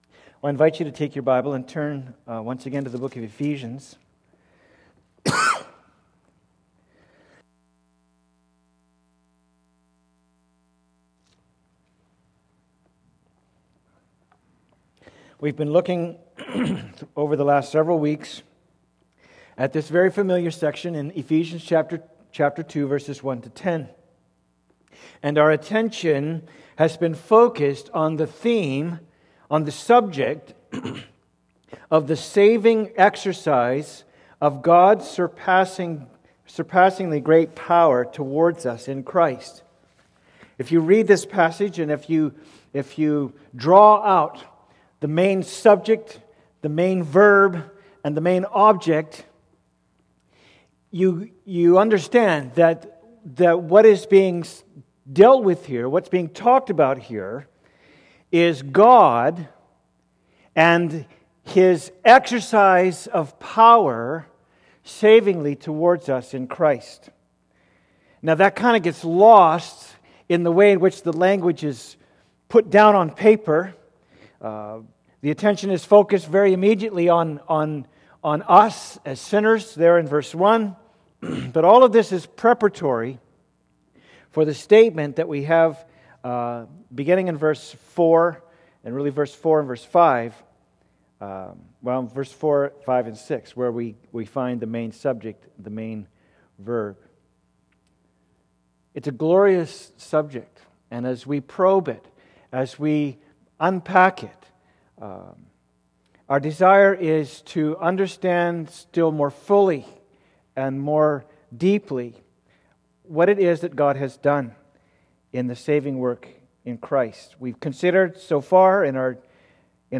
Passage: Ephesians 2:5a Service Type: Sunday Service